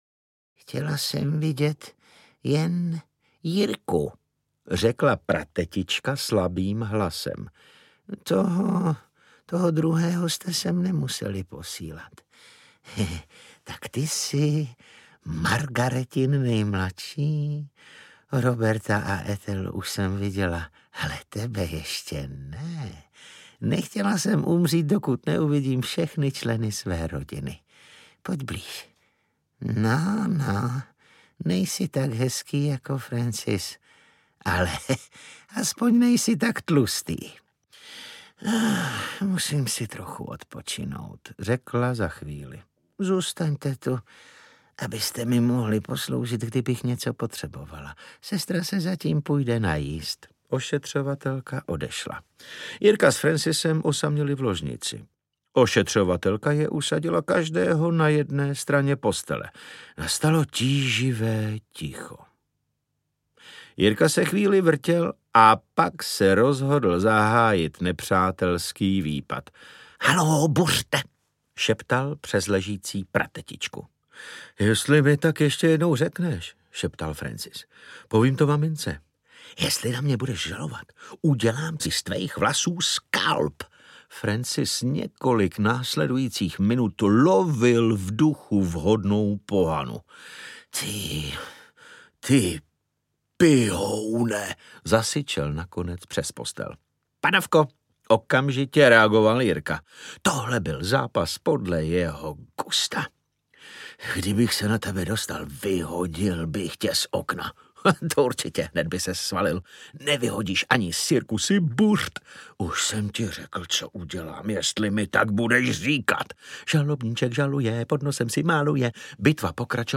Jirka postrach rodiny audiokniha
Ukázka z knihy
Čte David Novotný.
Vyrobilo studio Soundguru.